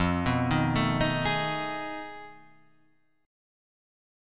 F7#9b5 Chord